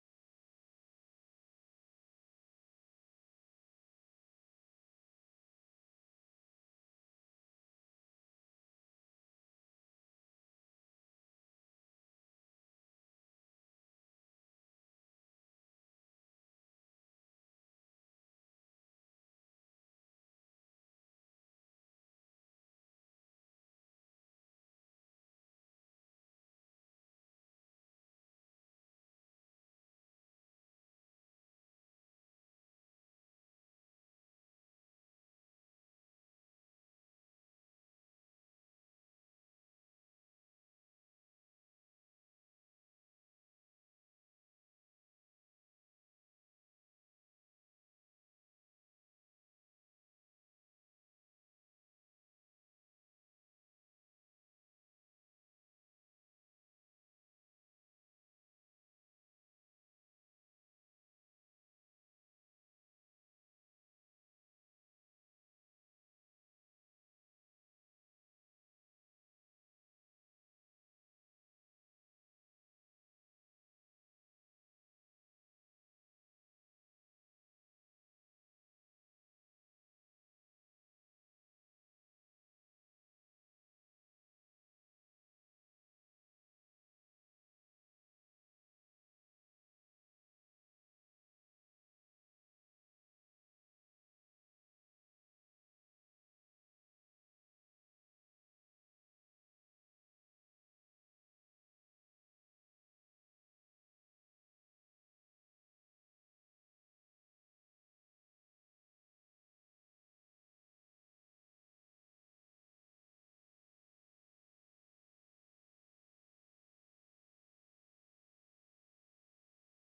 시온(주일1부) - 나 무엇하든지 주께 영광 돌리리
찬양대